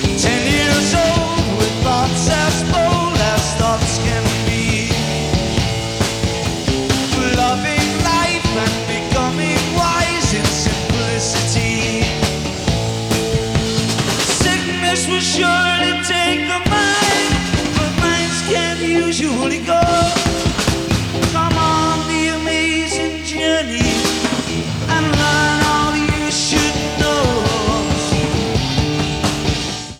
Format/Rating/Source: CD - B+ - Soundboard